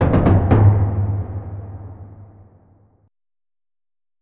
drums.ogg